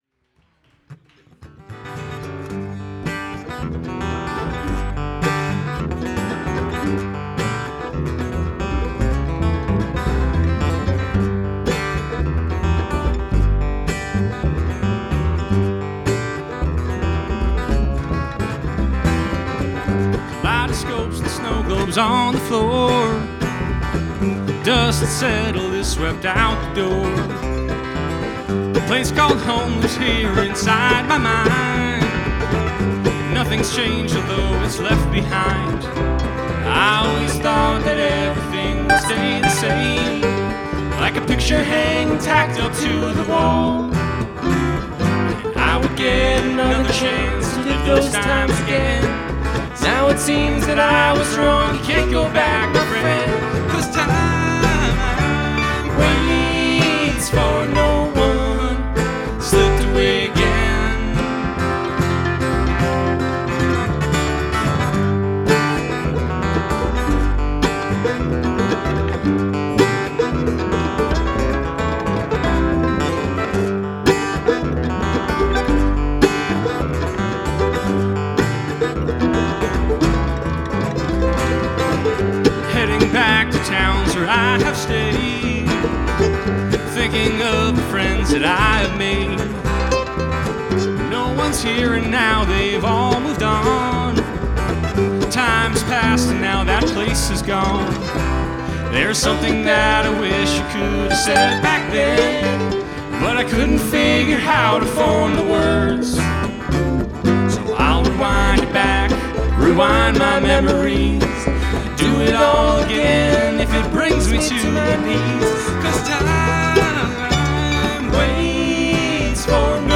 Guitar
Mandolin
Fiddle